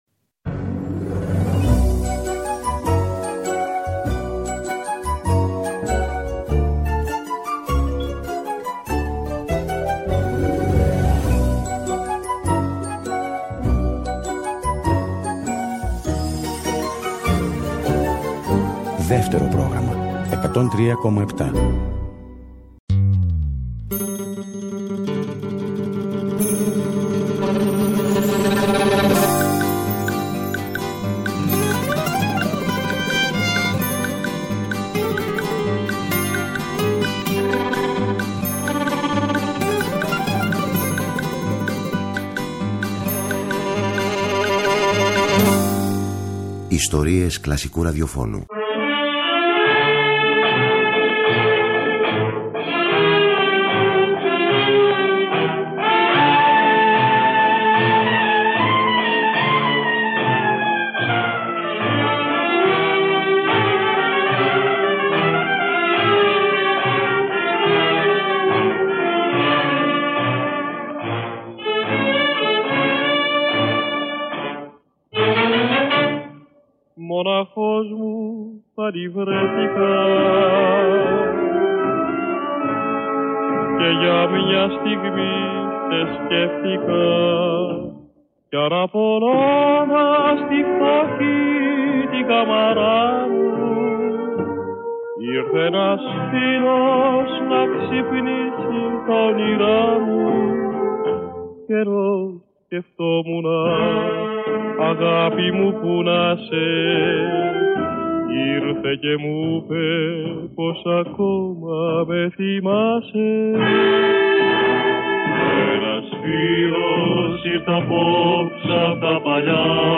Ορχήστρες και φωνές ανεπανάληπτες, από μια εποχή που είχε χρόνο για μουσική και συναίσθημα!